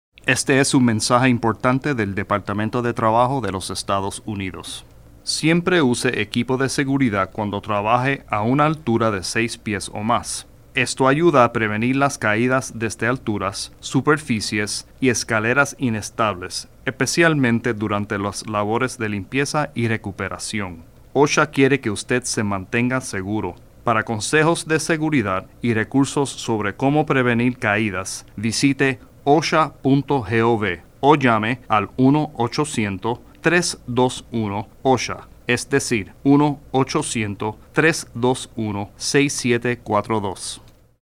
Public Service Announcements